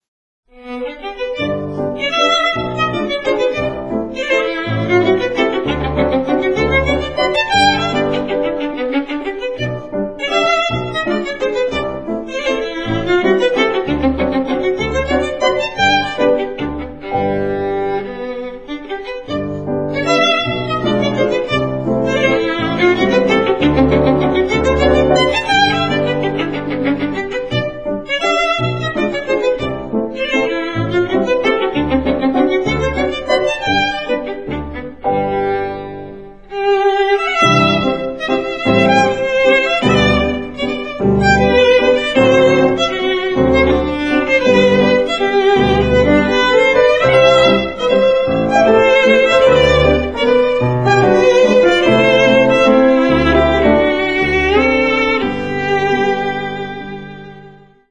Violine
Klavier